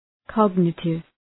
Προφορά
{‘kɒgnətıv}